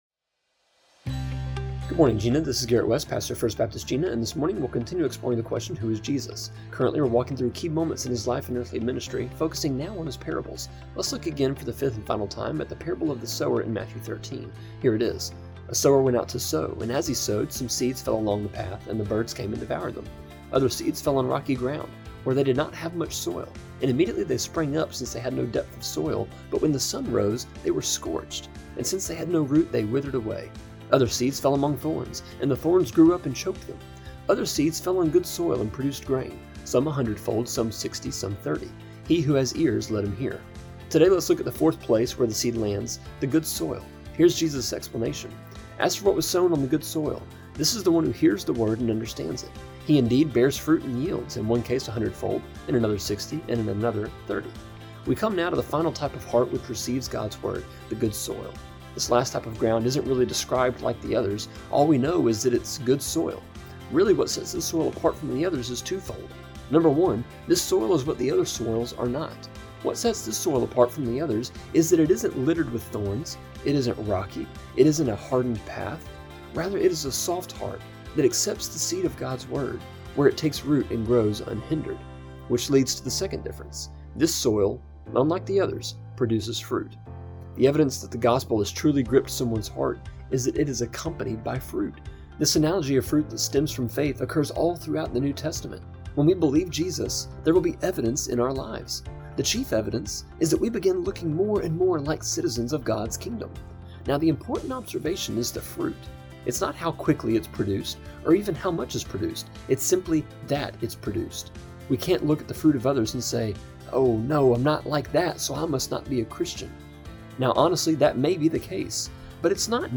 A three minute (-ish) devotion that airs Monday through Friday on KJNA just after 7am.